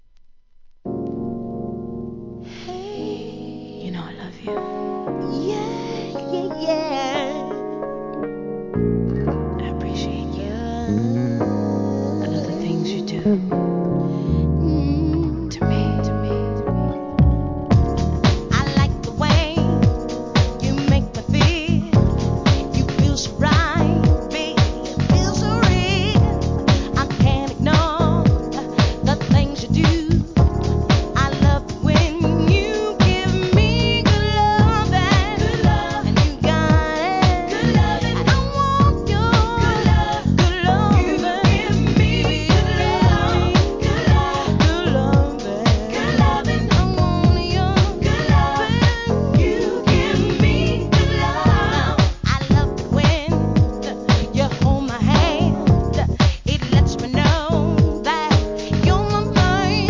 HIP HOP/R&B
当時インディーながら圧倒的な歌唱力で話題になったネオ・ソウル・クラシック！！